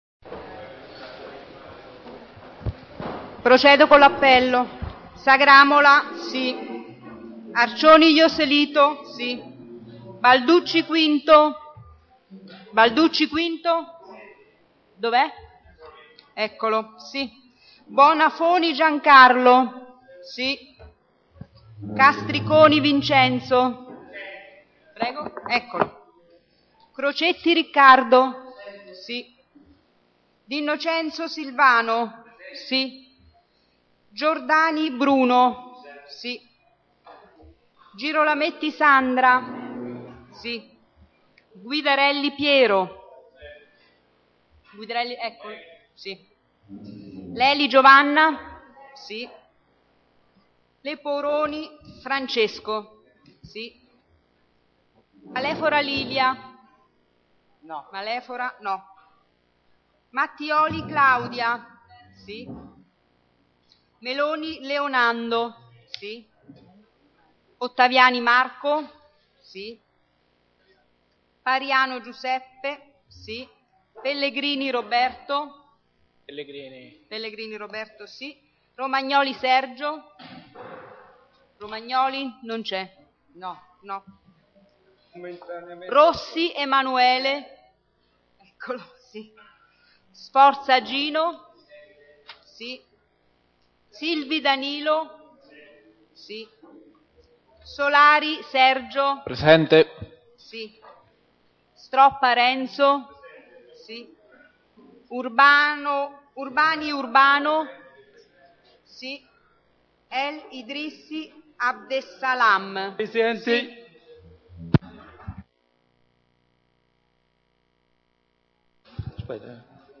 Ai sensi dell`art. 20, comma 7, dello Statuto Comunale e dell`articolo 14 del regolamento consiliare, il Consiglio Comunale e` convocato presso Palazzo Chiavelli - sala consiliare lunedi 16 luglio 2012 alle ore 15.30